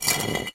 brick-move.mp3